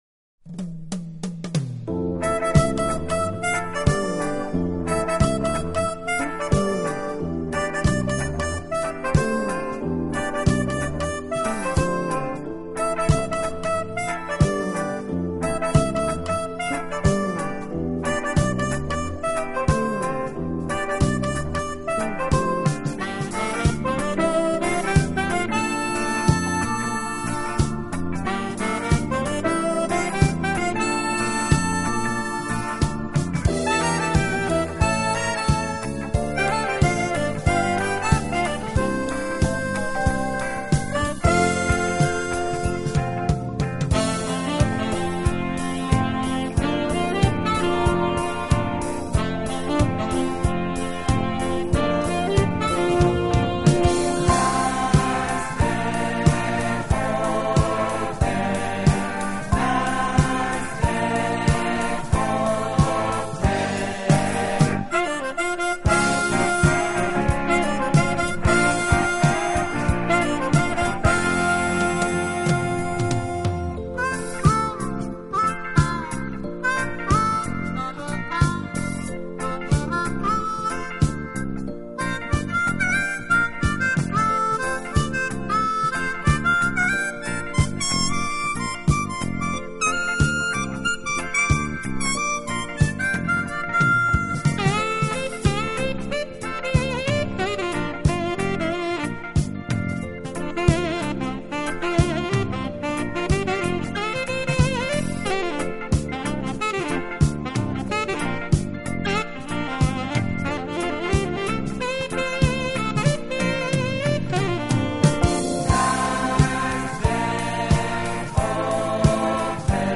harmonica
drums
bass
sax
keyboards